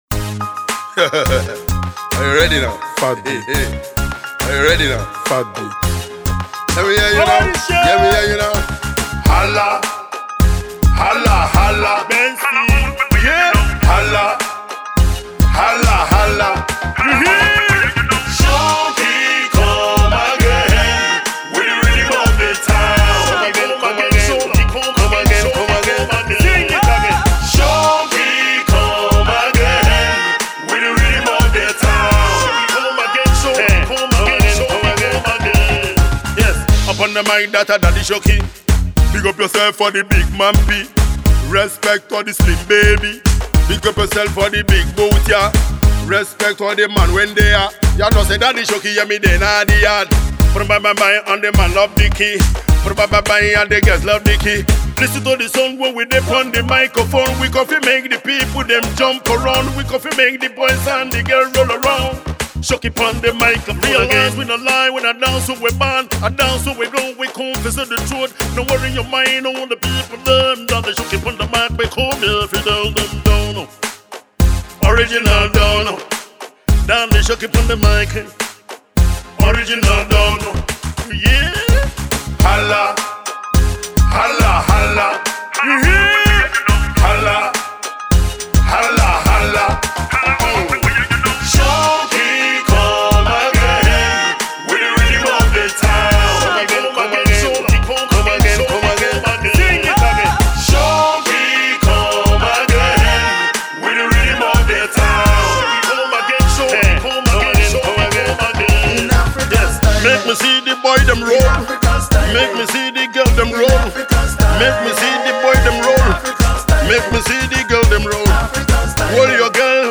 A mid tempo but feisty track for your pleasure titled